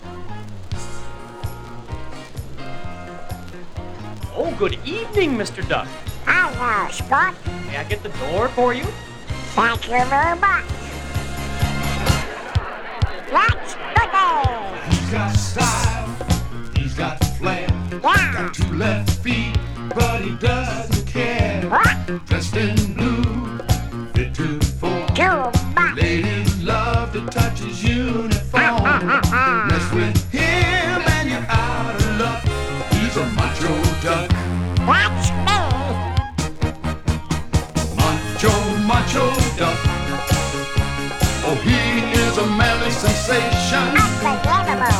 Pop, Disco, Novelty　USA　12inchレコード　33rpm　Stereo